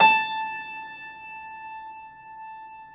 53c-pno17-A3.wav